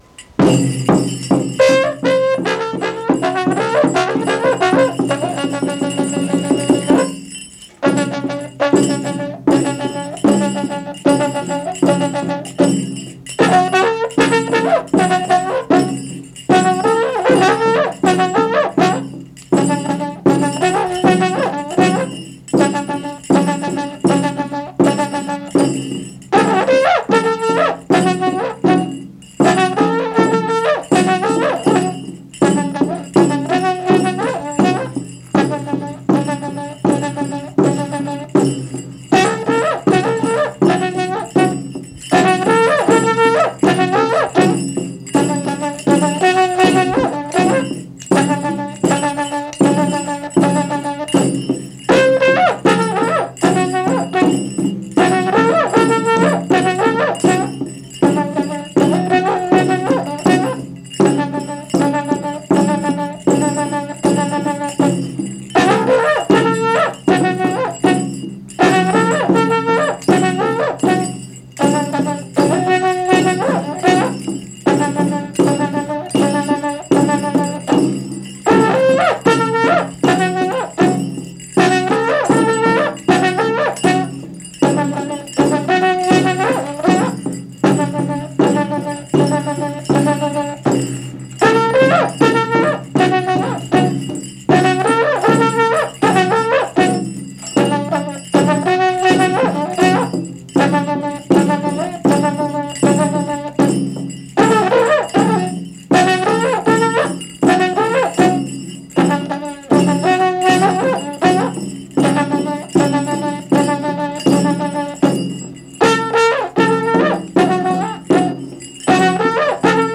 Música mapuche (Comunidad Cerro Loncoche, Metrenco)
Música vocal
Tradición oral